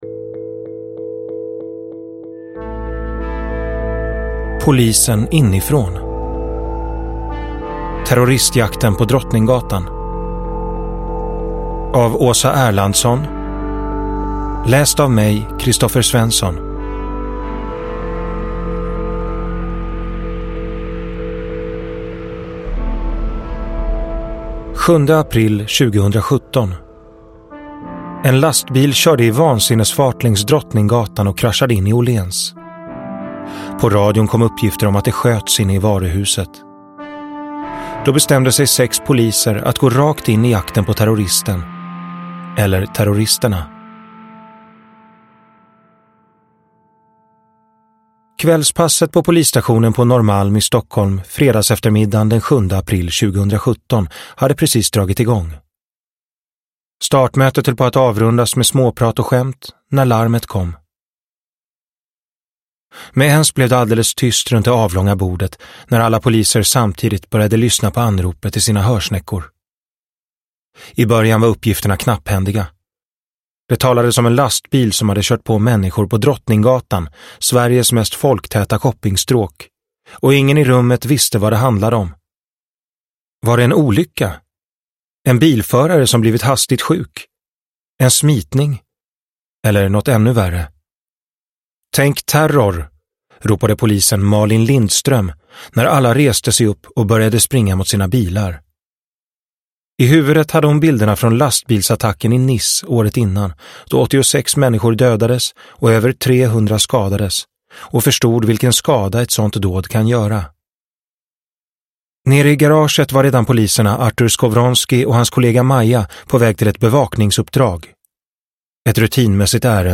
Terroristjakten på Drottninggatan – Ljudbok – Laddas ner